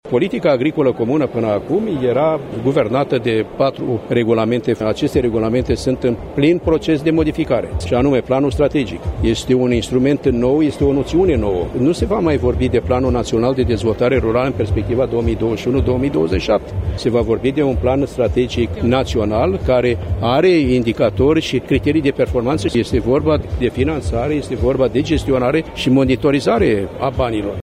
Politica Agricolă Comună (PAC) va fi modificată pentru perioada 2021-2027 şi va simplifica procedurile de accesare a fondurilor europene, a declarat ieri ministrul Agriculturii, Petre Daea. El a mai spus, la conferinţa Anuală a Clubului Fermierilor Români, că o altă modificare vizează trecerea de la conceptul de conformitate la cel de performanţă:
Petre Daea: